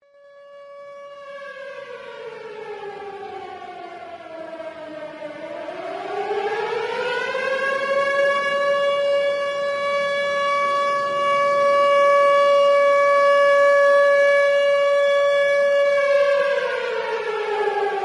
Download Tornado Siren sound effect for free.
Tornado Siren